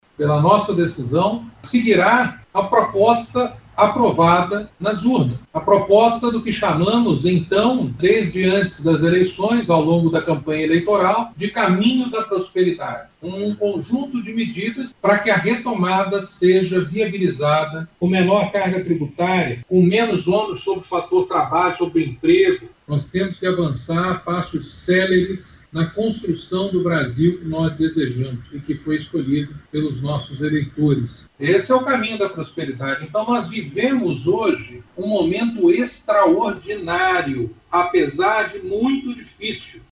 Sobre os planos de retomada, o presidente do CAS, Carlos da Costa, que é o secretário Especial de Produtividade, Emprego e Competitividade do Ministério da Economia, afirma que ela vai seguir o que foi decido pela população, na eleição.